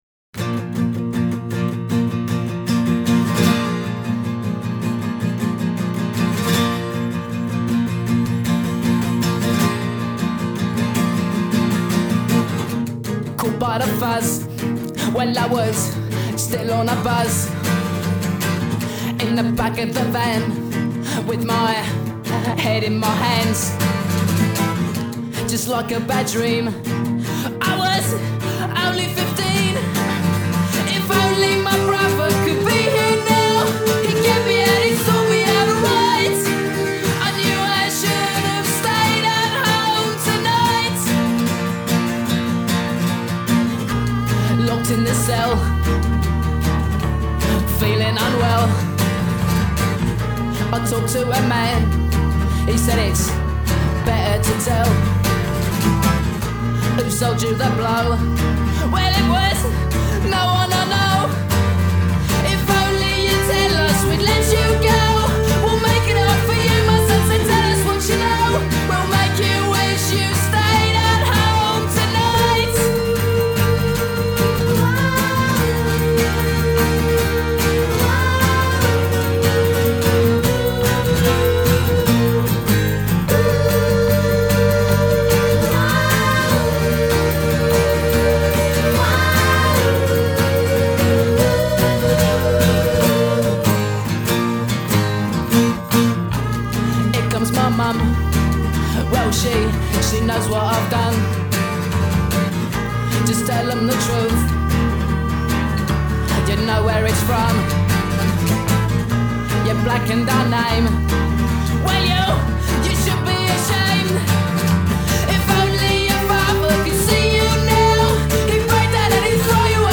The acoustic